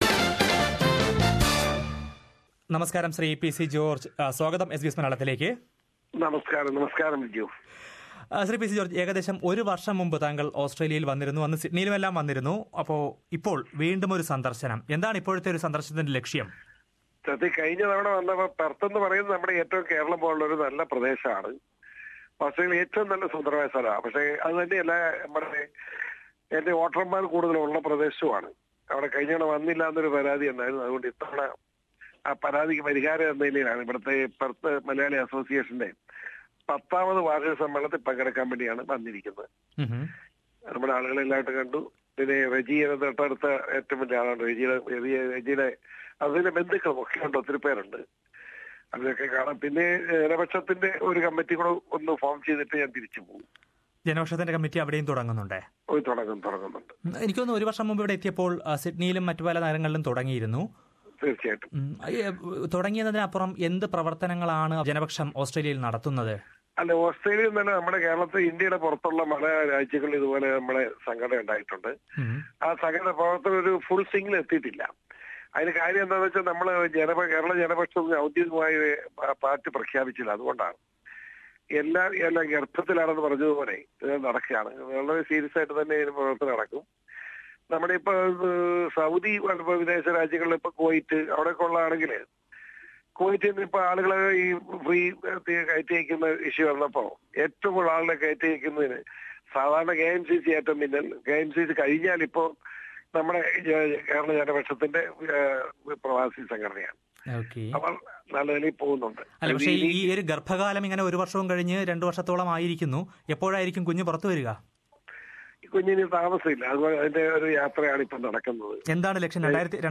Poonjar MLA and Janapaksham leader P C George said that it is high time that Kerala Congress politics should cease to exist in Kerala. Listen to his interview with SBS Malayalam, during his recent Australian visit.